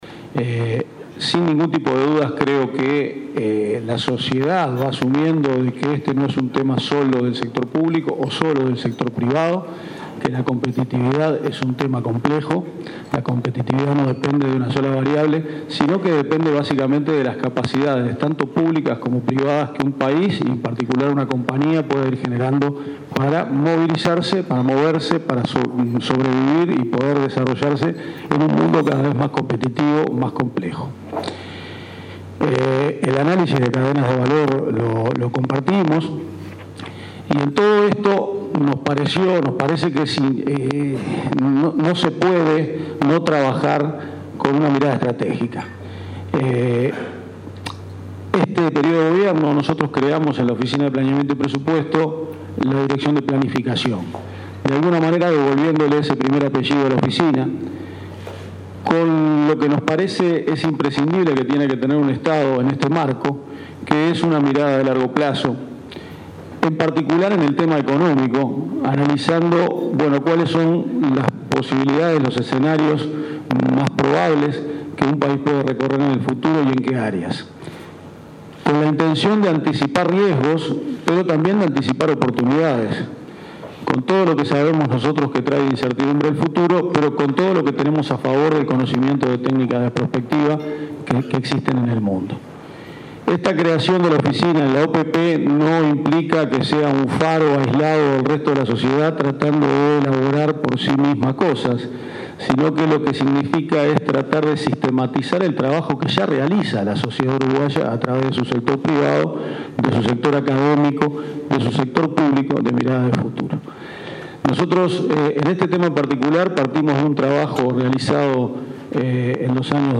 El director de OPP, Alvaro García, dijo que en abril se convocará al gabinete de transformación productiva y competitividad, integrado por los ministros del área económica. Durante el evento del Banco Mundial “Informe sobre lácteos y tecnologías de la información y comunicación”, resaltó el rol de la Dirección de Planificación para generar una mirada de largo plazo en el tema económico, anticipando riesgos y oportunidades.